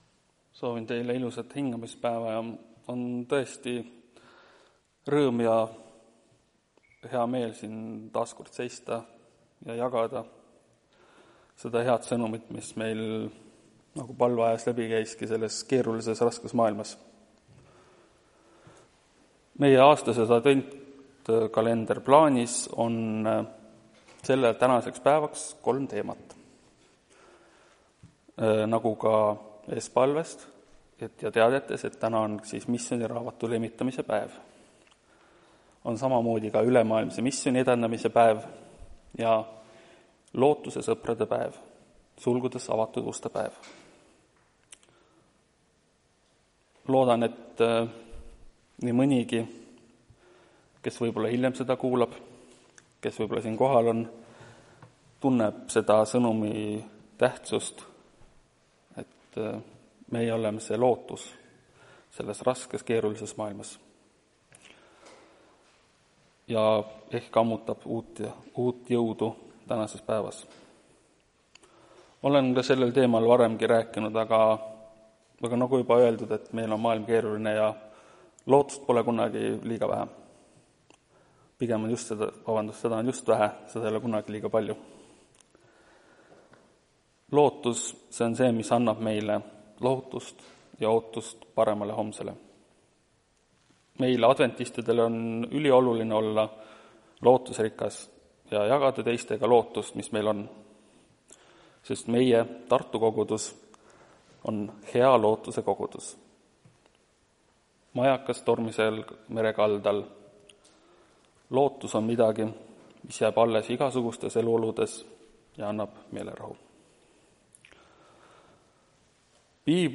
Tartu adventkoguduse 12.04.2025 hommikuse teenistuse jutluse helisalvestis.